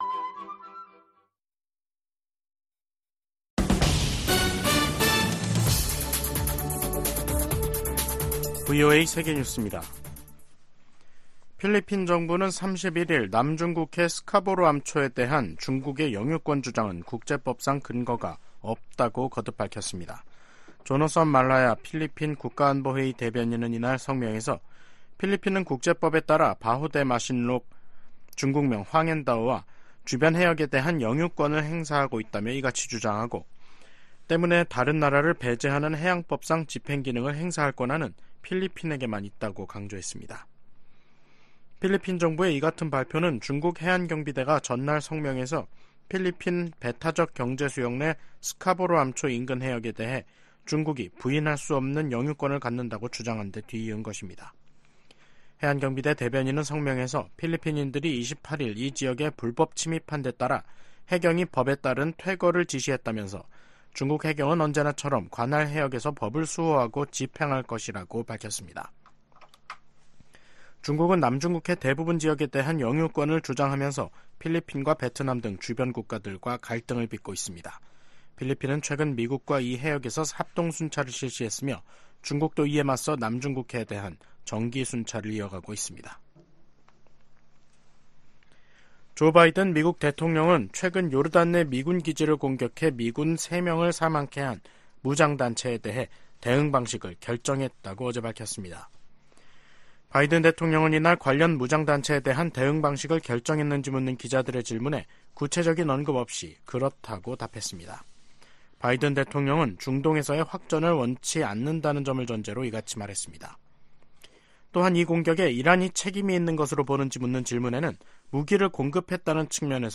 VOA 한국어 간판 뉴스 프로그램 '뉴스 투데이', 2024년 1월 31일 3부 방송입니다. 윤석열 한국 대통령은 북한이 총선을 겨냥한 도발을 벌일 것이라며 총력 대비해야 한다고 강조했습니다. 미 공화당 하원의원들이 자동차업체 포드 사와 계약을 맺은 중국 업체들이 북한 정부 등과 연계돼 있다고 밝혔습니다. 국무부는 북한이 10대 소년들이 한국 드라마를 봤다는 이유로 12년 노동형을 선고받았다는 보도에 대해 처벌의 가혹성을 깊이 우려한다”고 밝혔습니다.